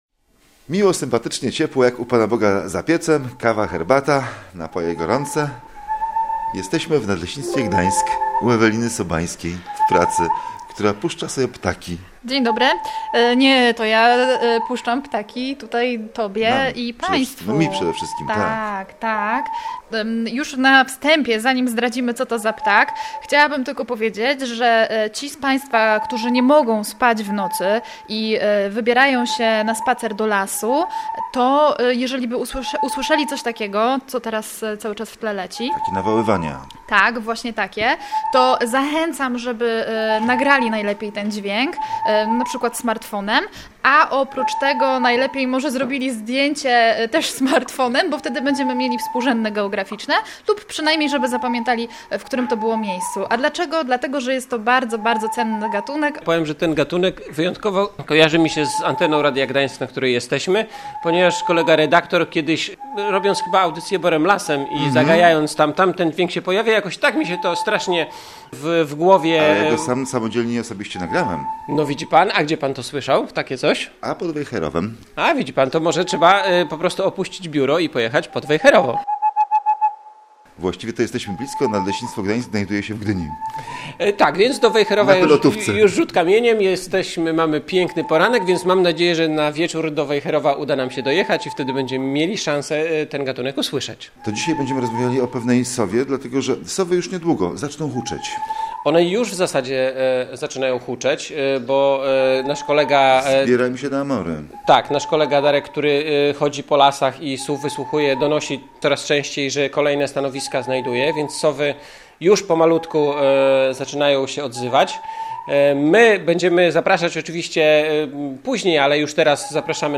W audycji rozmawiamy nie tylko o włochatkach.